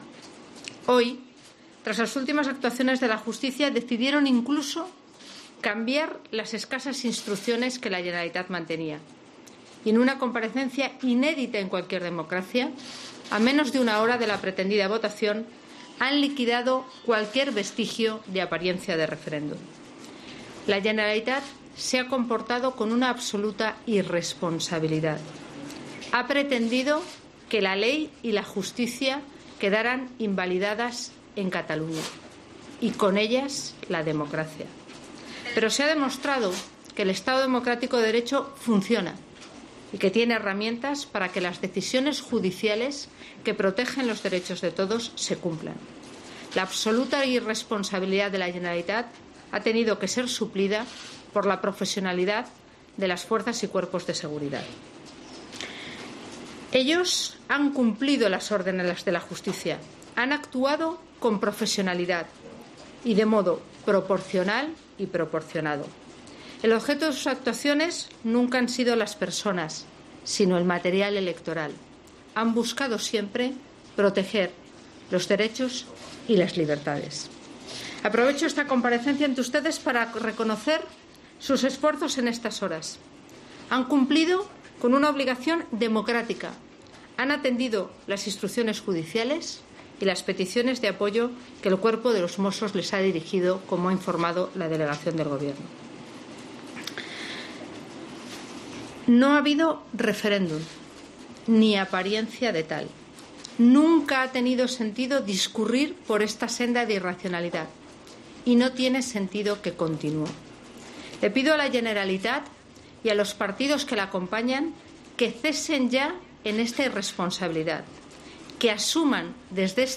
Declaración institucional de Soraya Saénz de Santamaría 1-O